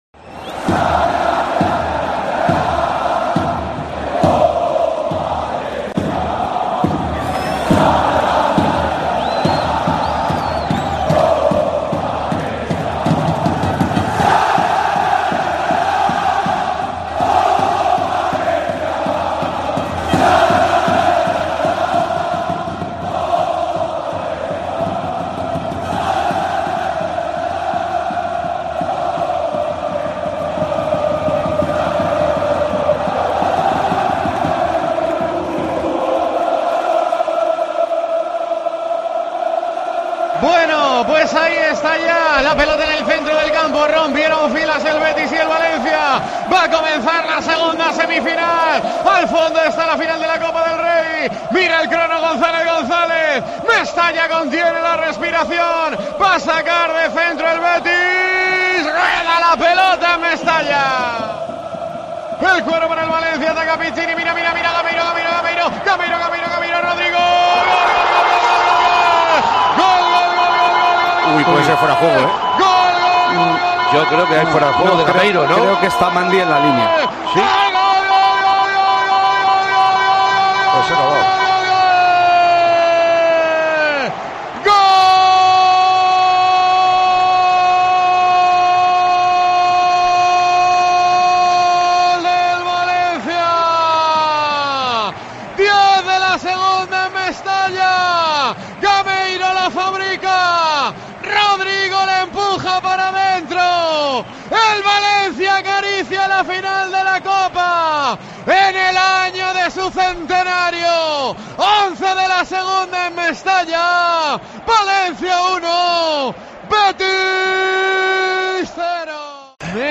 ¡Revive el pase a la final del Valencia! Todos los sonidos de COPE en una noche mágica de Tiempo de Juego.